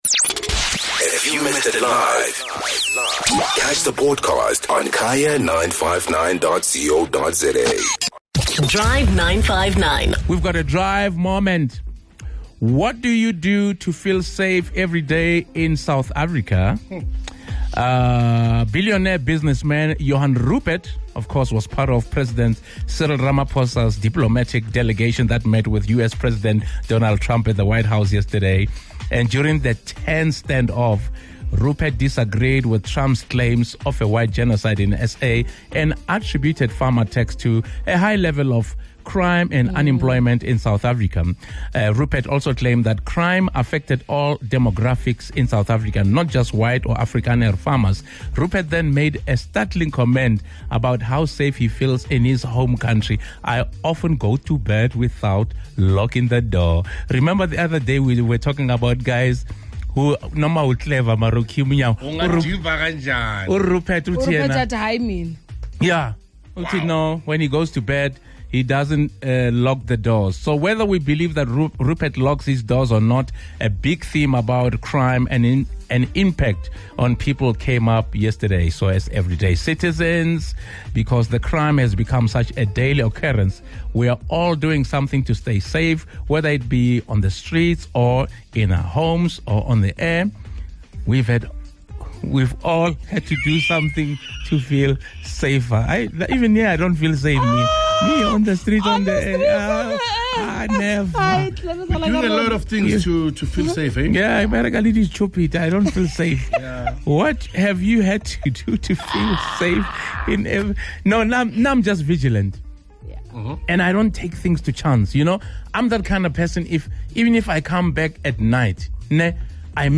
As everyday citizens, because crime has become such a daily occurrence, we're all doing something to stay safe, whether it be on the streets, or in our homes. Take a listen to what our listeners and the Drive 959 team had to say.